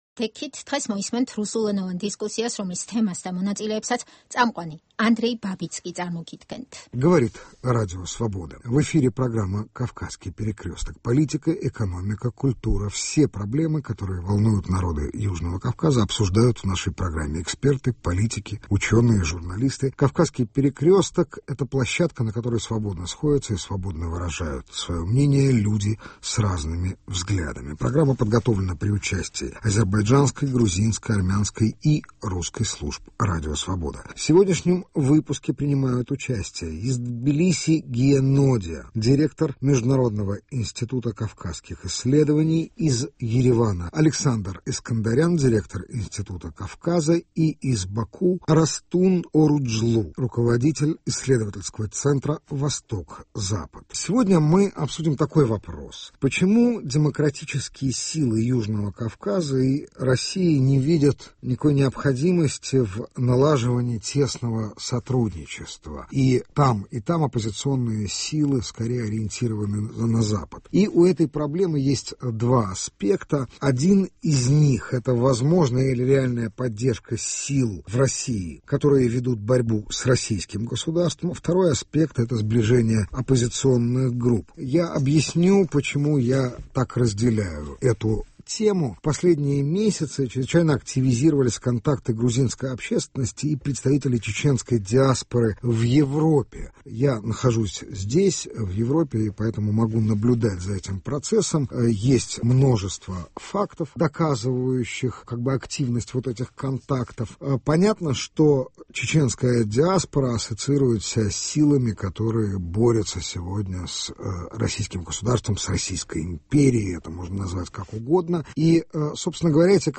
გადაცემას უძღვება ანდრეი ბაბიცკი.